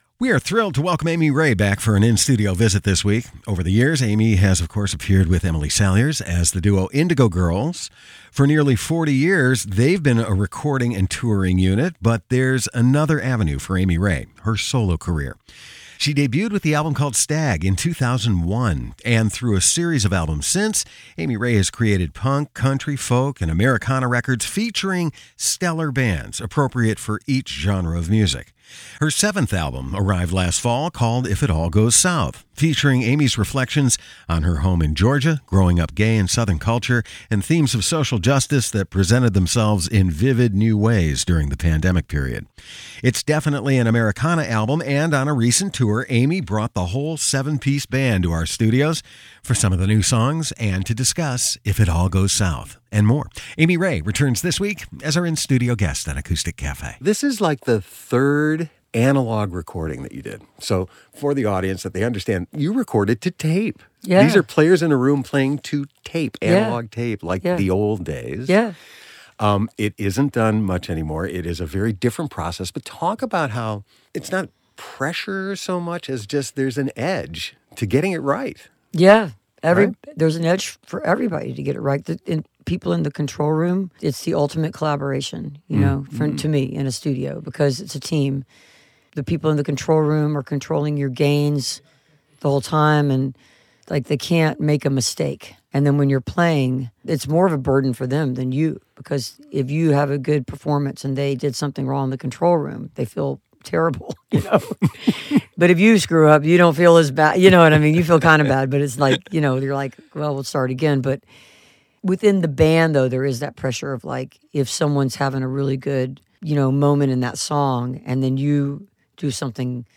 (webstream capture)
05. interview (3:37)